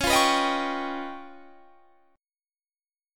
C#9b5 Chord
Listen to C#9b5 strummed